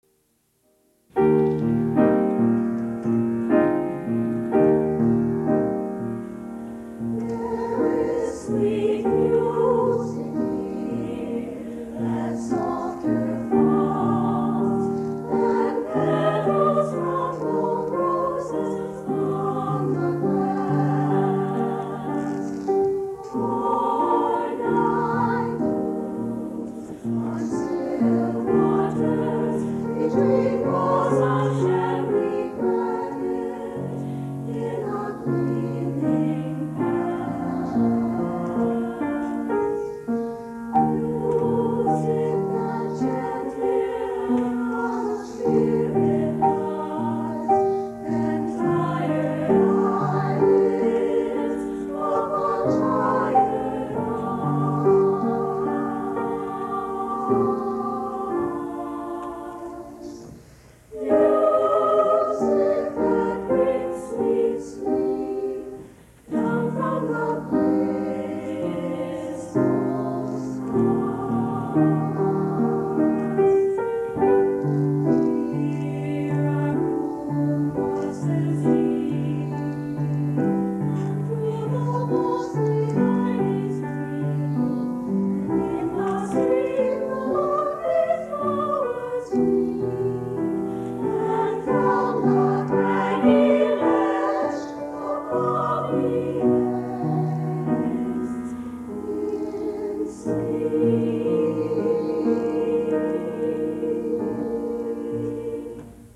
Highland Park, MI, High School Concert Choirs, 1954-1969
"Motet, There Is Sweet Music Here", by J. Clements - HPHS, 1963